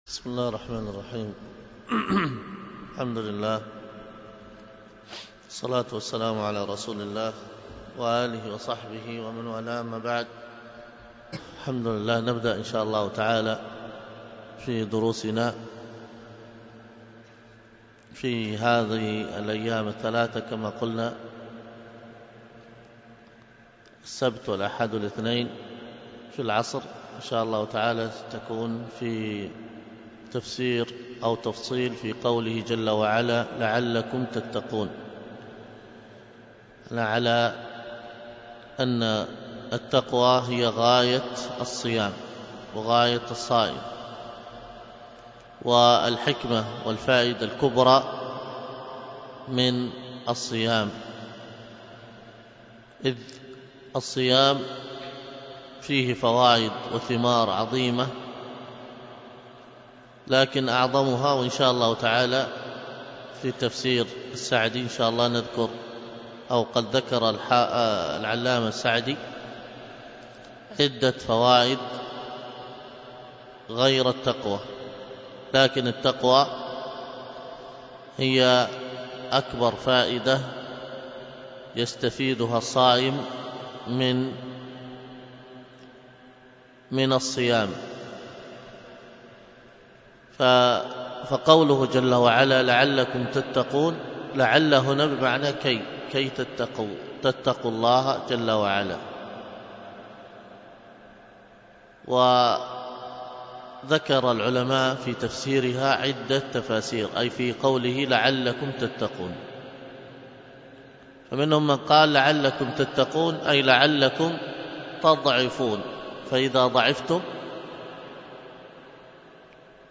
الخطبة بعنوان من حكم الله في البلاء، وكانت بمسجد التقوى بدر الحديث بالشحر ١٠ رجب ١٤٣٥هـ ألقاها